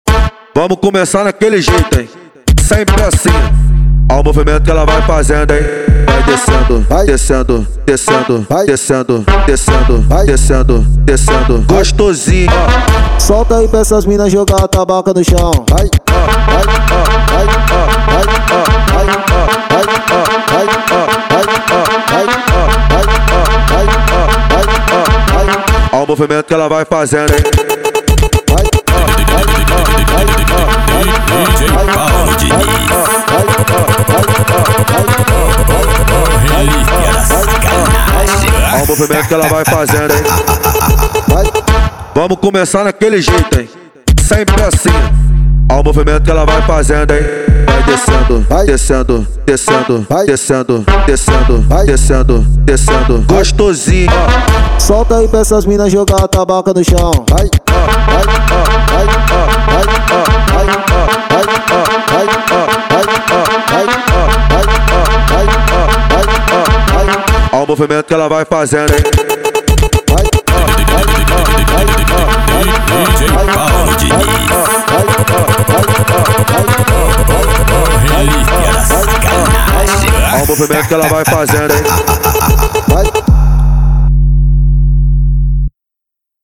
Dançante.